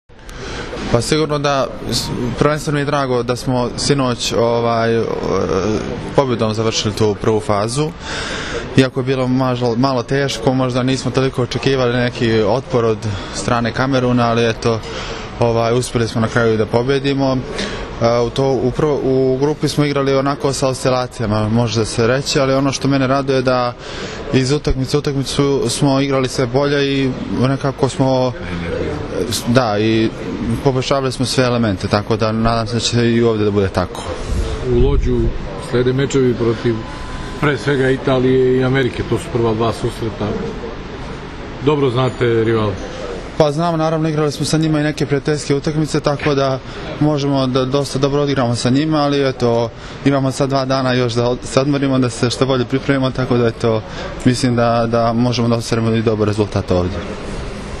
IZJAVA SAŠE STAROVIĆA